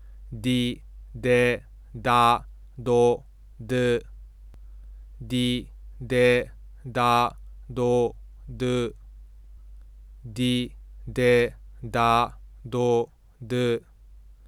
/da/（3番目） や /do/（4番目）では母音の始端でF2が急激に下降しているのに対し，/di/や/de/ではむしろ若干上昇しています。
didedadodu.wav